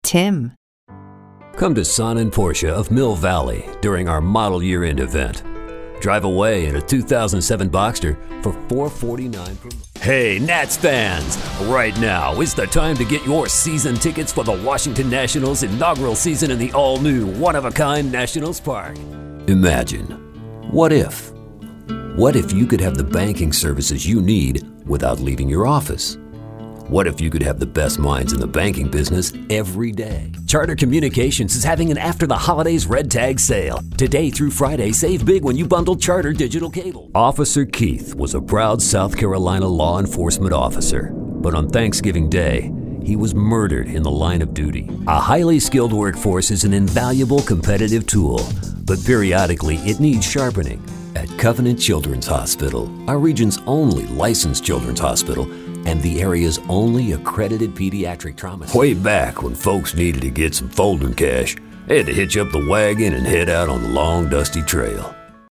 english-showcase, political